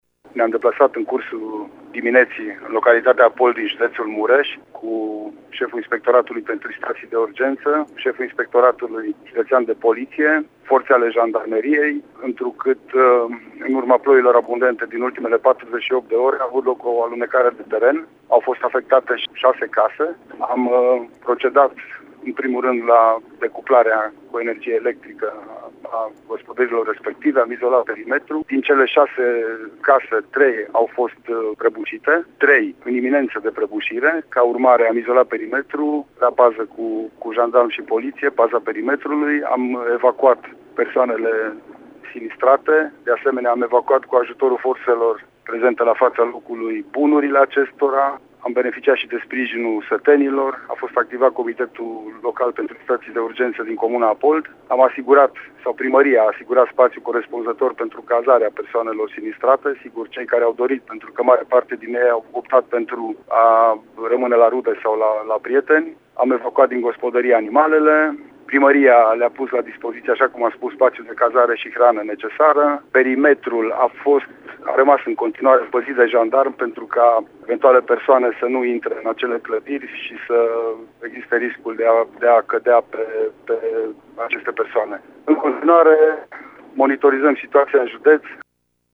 Reprezentanții Prefecturii Mureș, împreună cu cei ai Inspectoratului pentru Situații de Urgență și ai Inspectoratului Județean de Poliție au fost astăzi în Apold și au luat măsurile care se impun în astfel de situații. Prefectul județului Mureș, Lucian Goga: